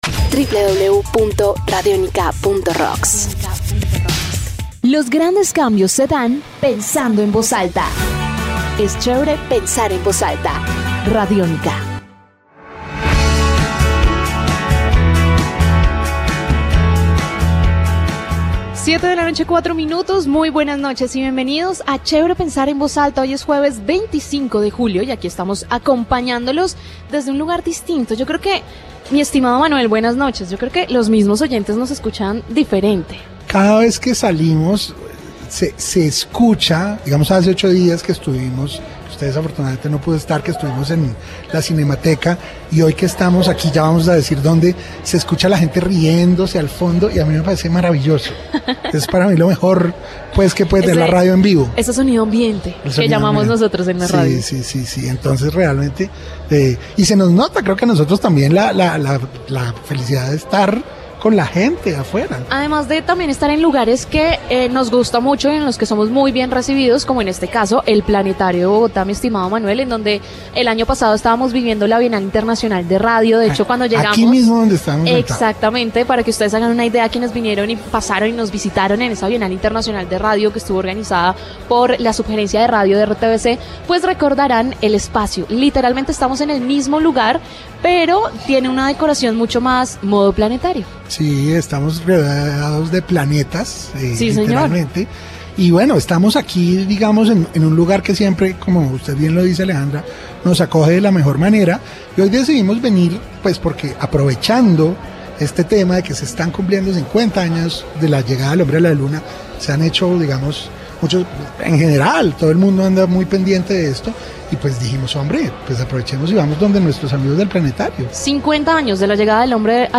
Desde el Planetario de Bogotá para celebrar sus 50 años y hablar de la llegada del hombre a la luna.
Y por ello en Chévere pensar en voz ALTA hablamos astronomía, desde el icónico planetario.